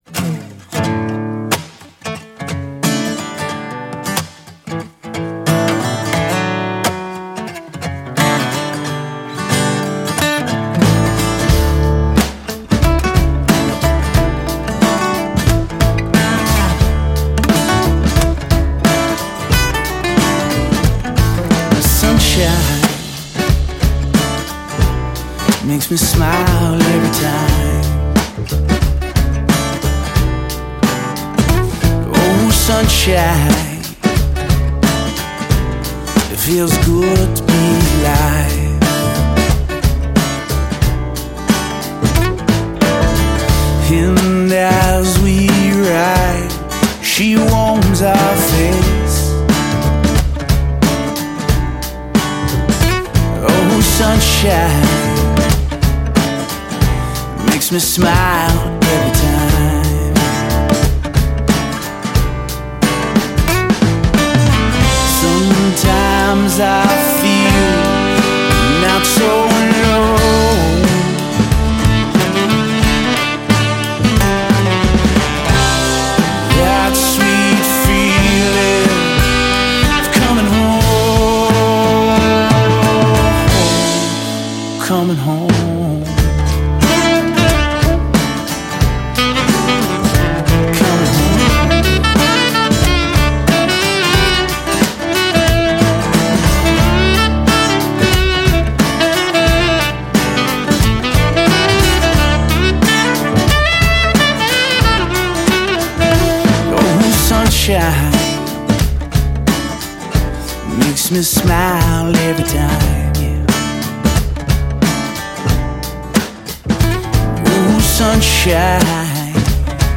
At Tank Recording Studio